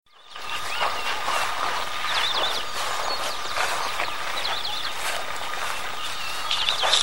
Many-colored Chaco Finch (Saltatricula multicolor)
Life Stage: Adult
Location or protected area: Mina Clavero
Condition: Wild
Certainty: Recorded vocal